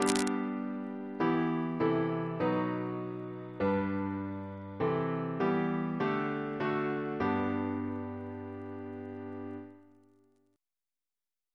Single chant in G Composer: Vincent Novello (1781-1861) Reference psalters: H1940: 652